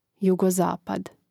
jugozápad jugozapad